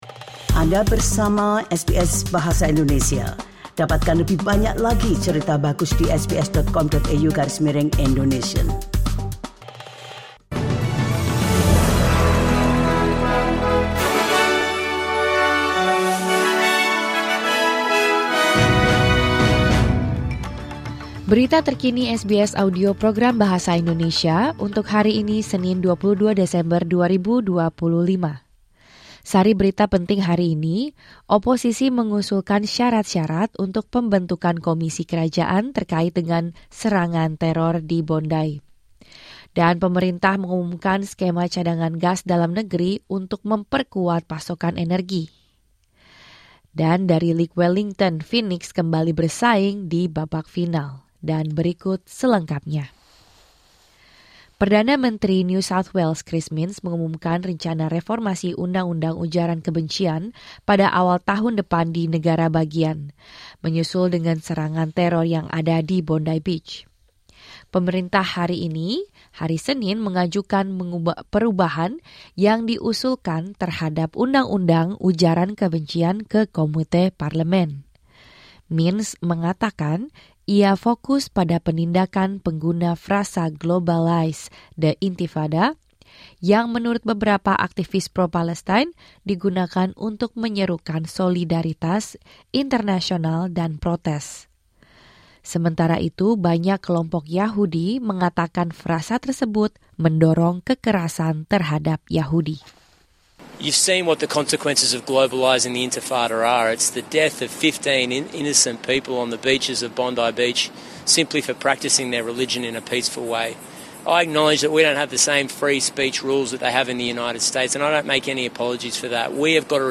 Berita Terkini SBS Audio Program Bahasa Indonesia – Senin 22 Desember 2025